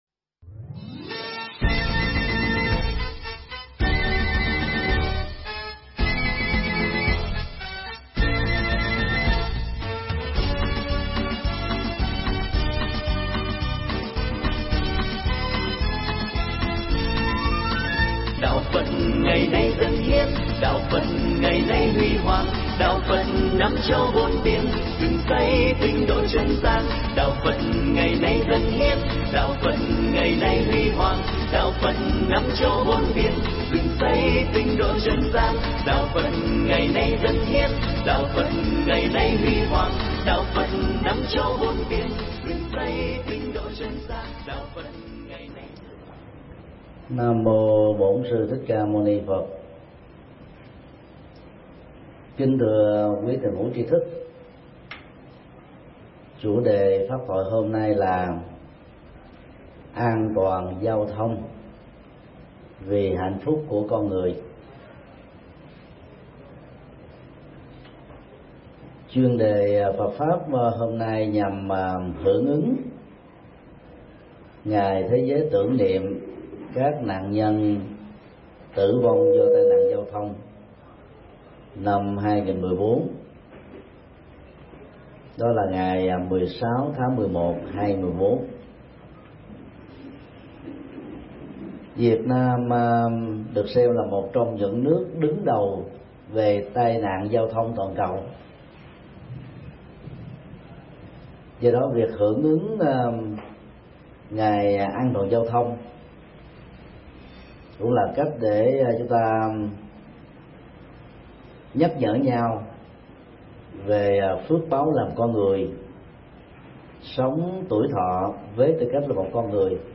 Mp3 Pháp Thoại An toàn giao thông vì hạnh phúc con người – Thầy Thích Nhật Từ Giảng tại cơ sở sinh hoạt tạm chùa Giác Ngộ, ngày 2 tháng 11 năm 2014